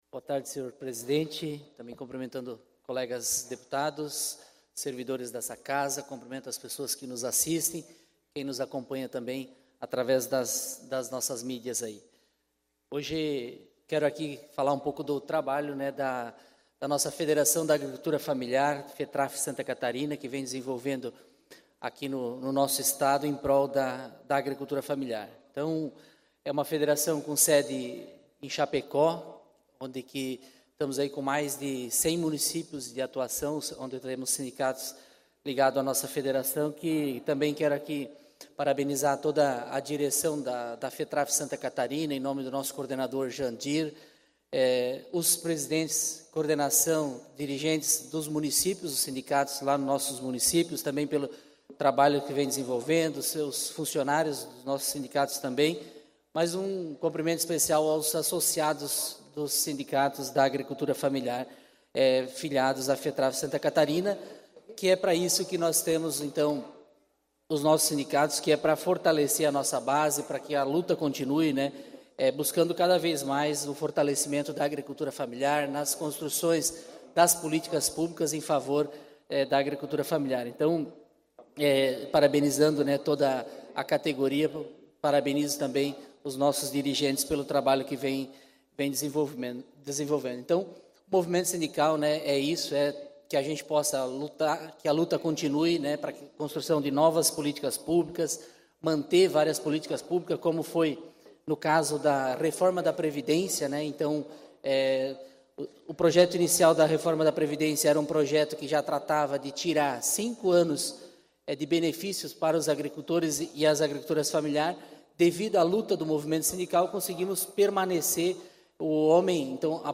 Pronunciamentos da sessão ordinária desta quarta-feira (29) - Tarde